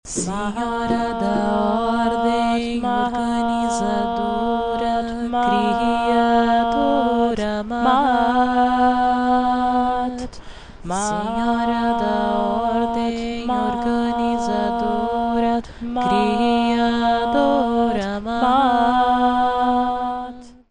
É mais um mantra que uma canção, é um mantra para entrar em transe e meditar com Ela. Na minha tentativa de exlicar como ela deveria ser cantada eu fiz uma tentativa meio tosca de utilizar um programa para sobrepôr minha própria voz explicando como duas pessoas ou mais cantam este mantra.